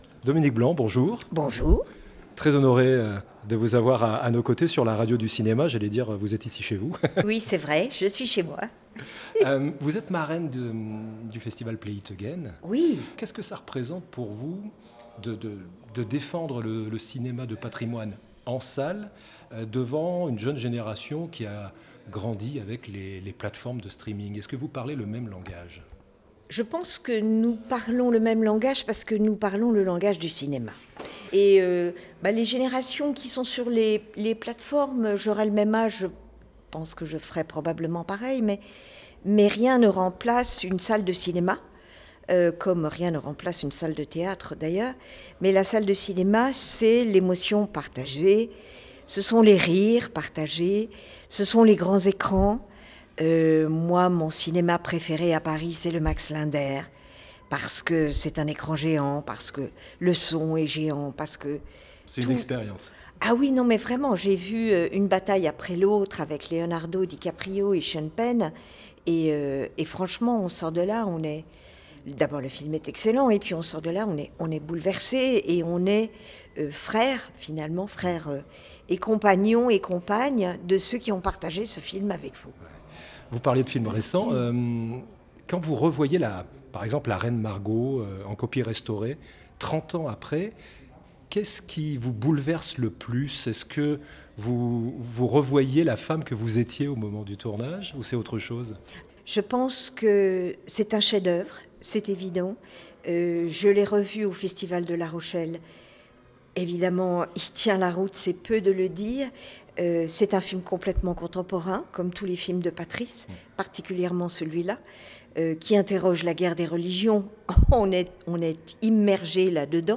Dominique Blanc, sociétaire de la Comédie-Française et comédienne aux quatre César, était l’invitée de La Radio du Cinéma dans le cadre du festival Play It Again. Marraine de l'édition 2025, elle partage son attachement viscéral au cinéma de patrimoine, ses souvenirs indélébiles avec Patrice Chéreau, et un acte fort : la restitution de sa Légion d’honneur.